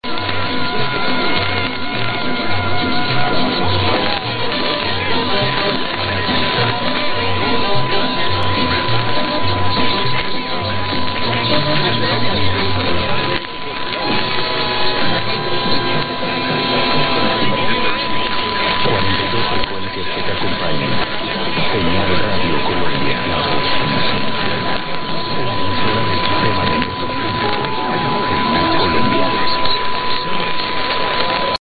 Receiver: Microtelecom Perseus
Antenna: north-null cardioid-pattern SuperLoop, 15 m vertical by 20 m horizontal, base height 1.2 m